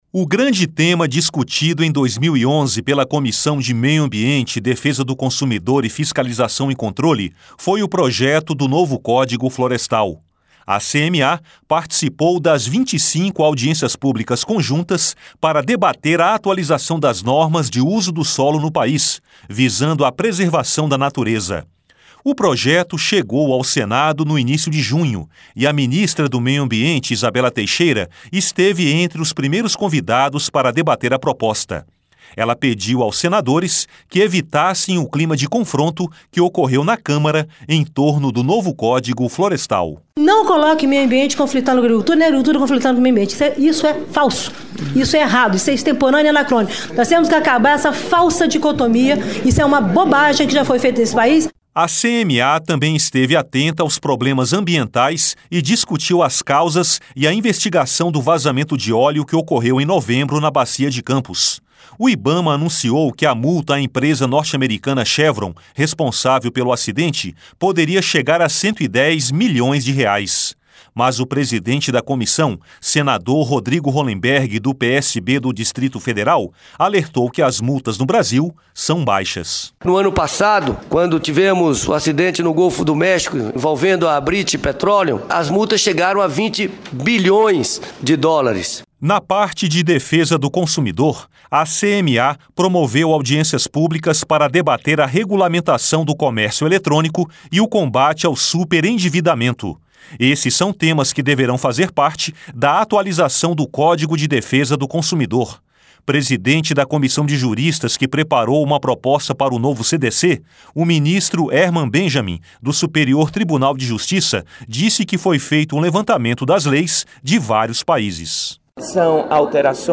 (REPÓRTER) O grande tema discutido em 2011 pela Comissão de Meio Ambiente